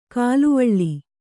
♪ kāluvaḷḷi